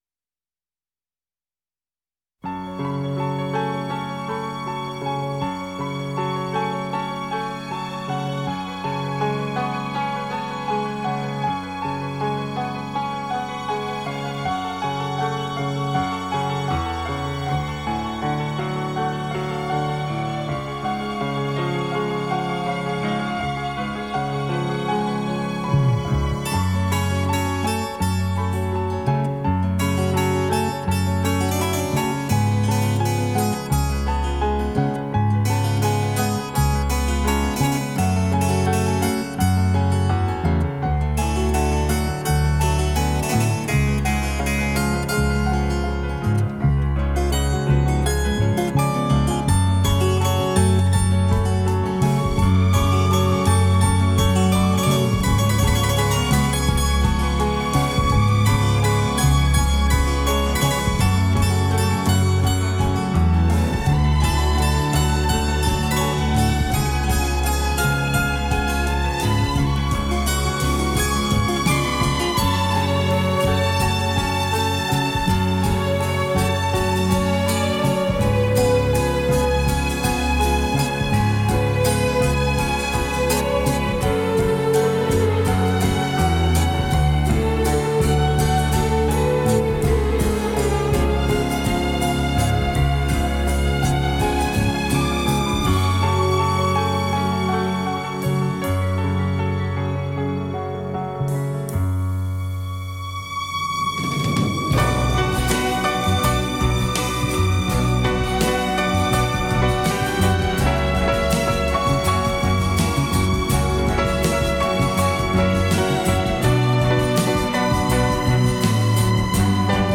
在于结构简单，节奏明快，旋律动听，具有亲切明了的内涵，